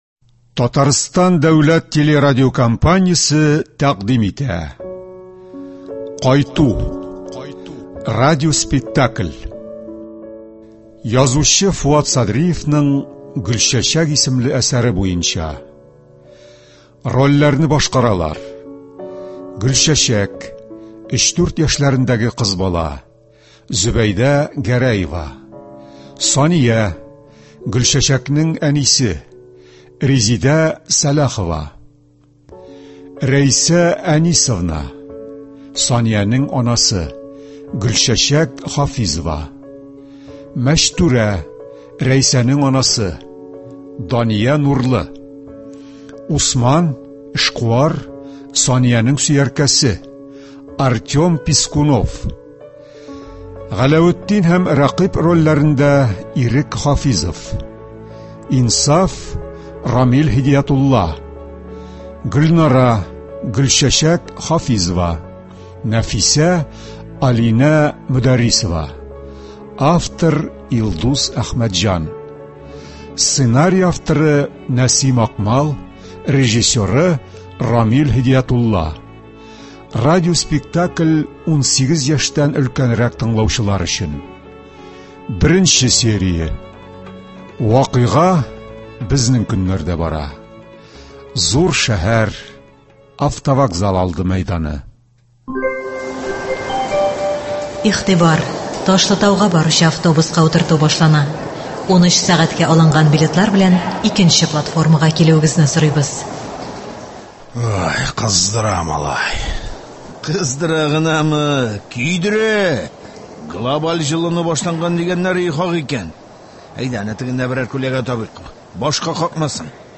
Радиоспектакль (17.12.23)